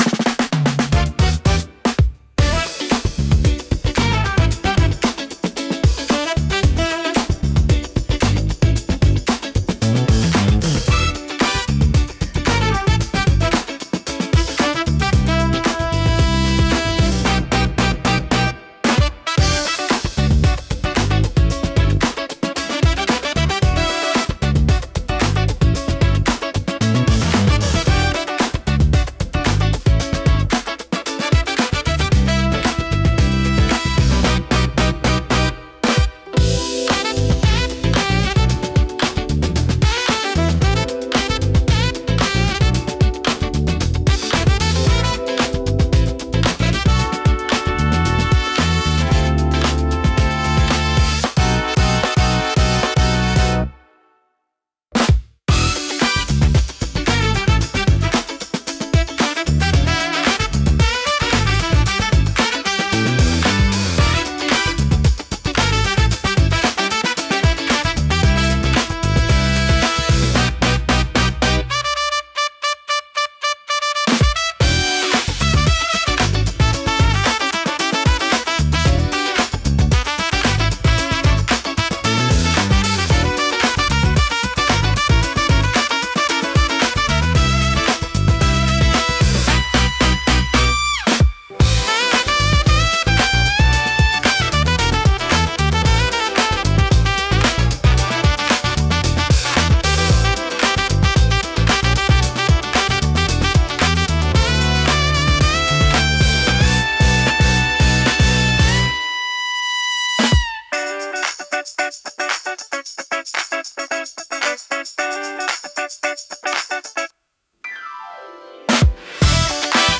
心地よいリズムが聴く人を踊らせ、楽しい雰囲気を盛り上げる効果があります。躍動感と活気に満ちたジャンルです。